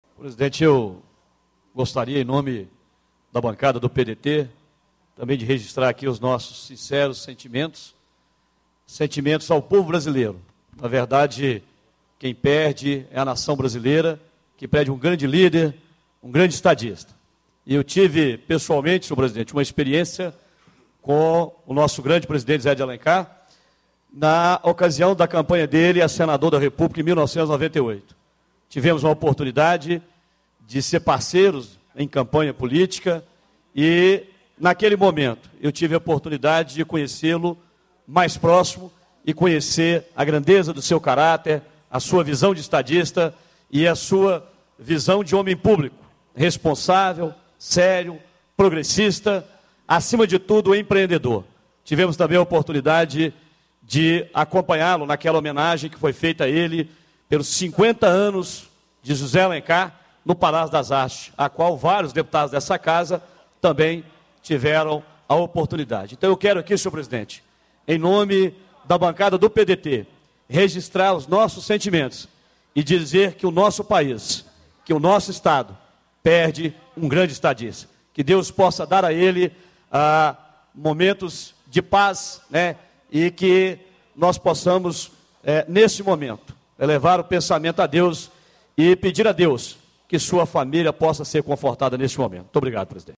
Reunião de Plenário - Pronunciamento sobre o ex-vice-presidente da república, José Alencar
Discursos e Palestras